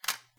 sfx_reload_3.mp3